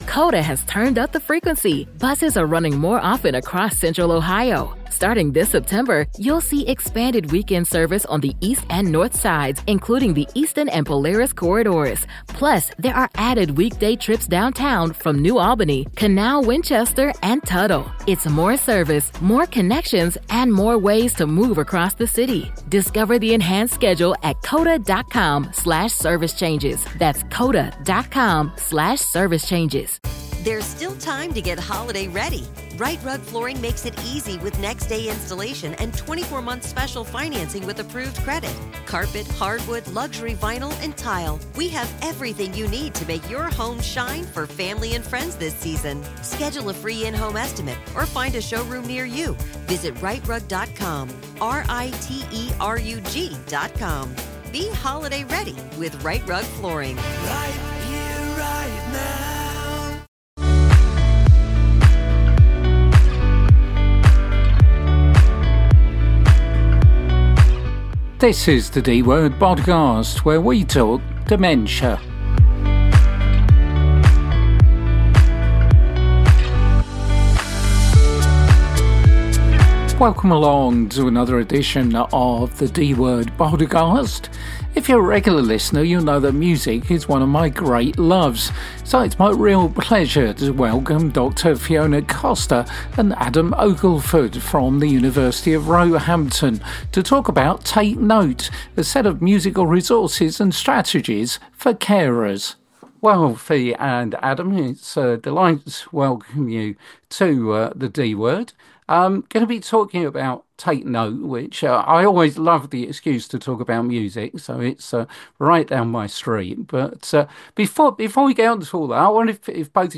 The ‘D’ Word is the UK’s only dementia-focused radio show.